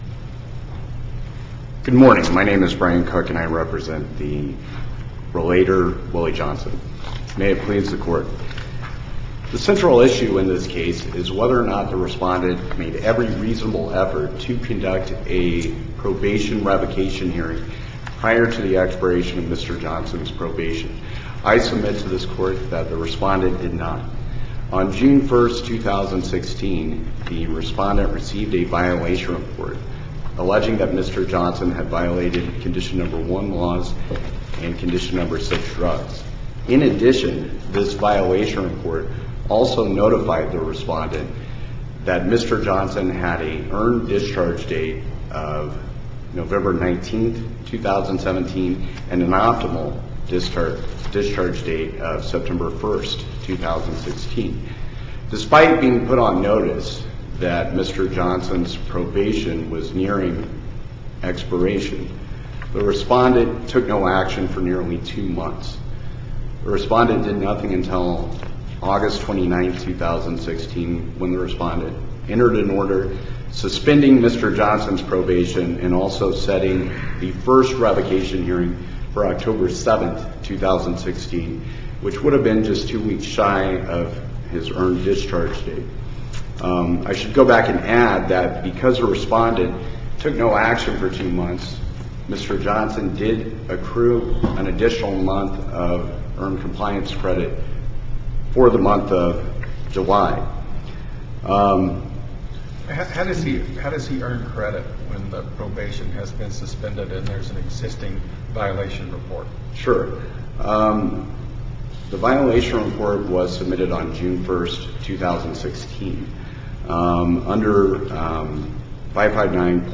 MP3 audio file of arguments in SC95976